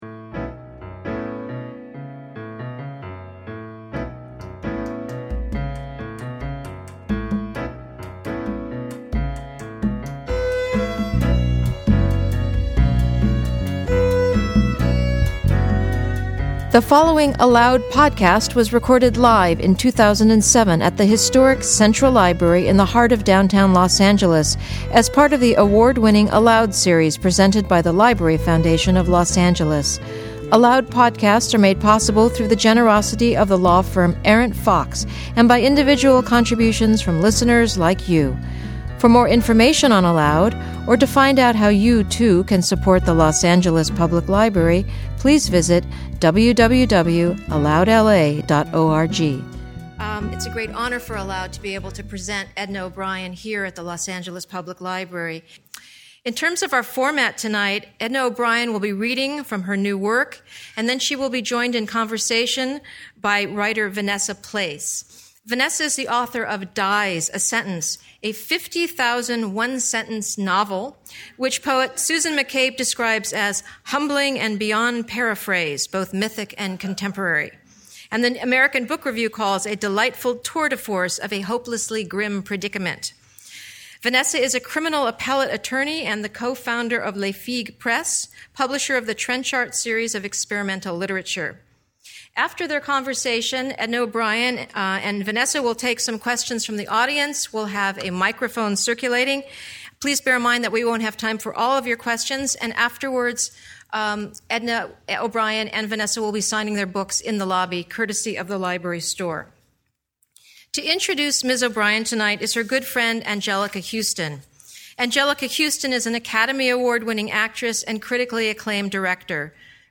In conversation with writer
special introduction by Anjelica Huston